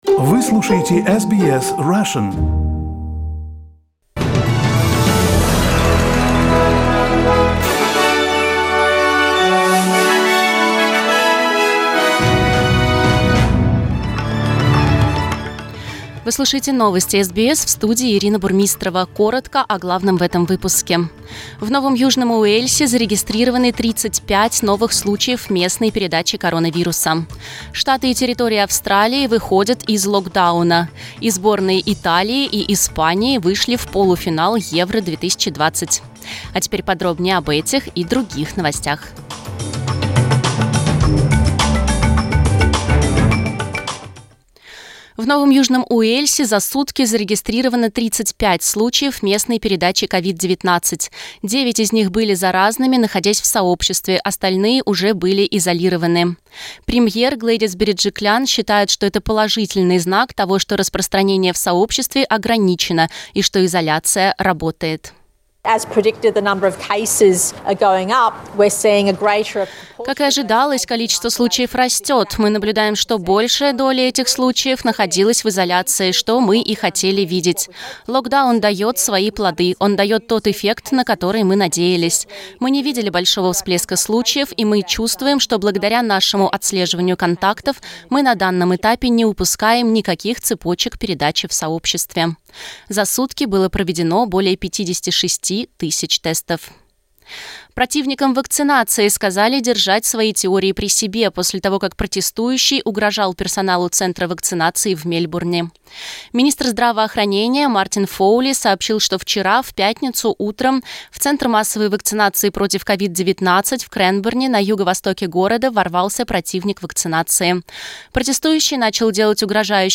Новости SBS на русском языке - 3.07